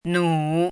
汉字“努”的拼音是：nǔ。
“努”读音
努字注音：ㄋㄨˇ
国际音标：nu˨˩˦
nǔ.mp3